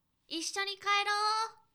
ボイス
ダウンロード 女性_「一緒に帰ろう」
リアクション女性